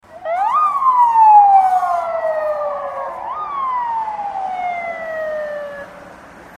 Police Siren Sounds ringtone free download
Sound Effects